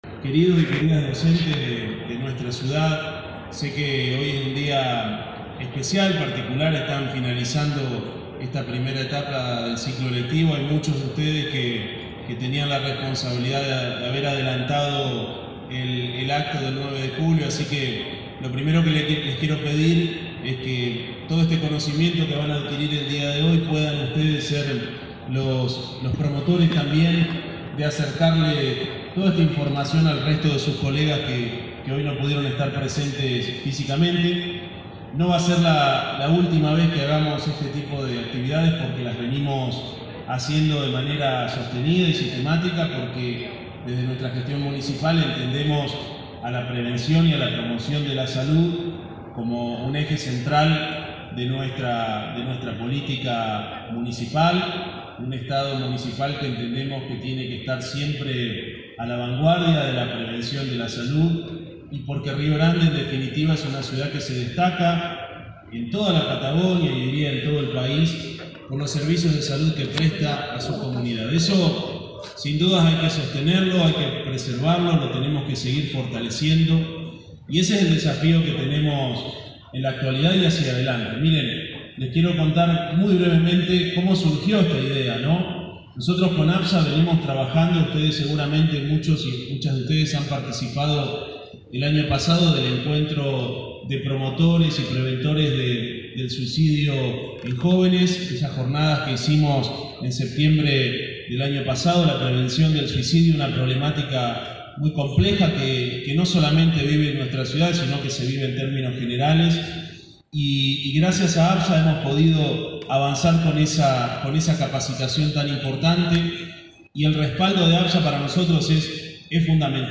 Audio Intendente Martín Pérez